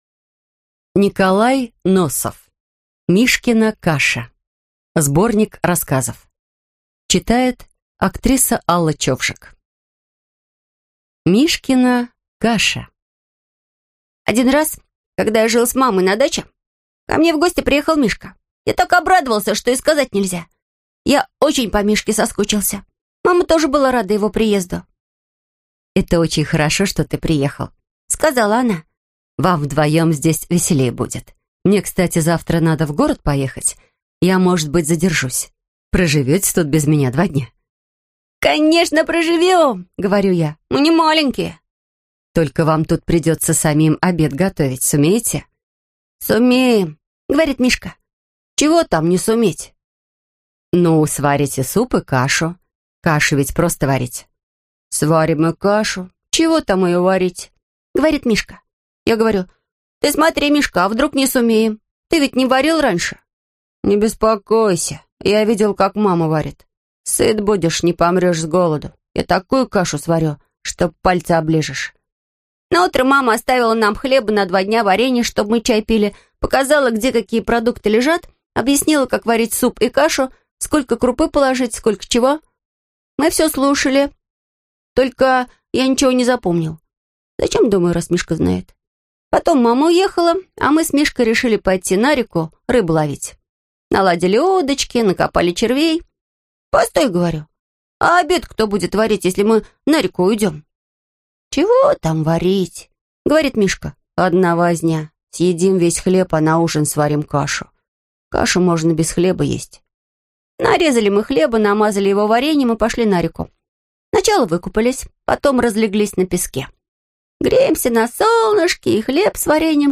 Аудиокнига Мишкина каша (сборник) | Библиотека аудиокниг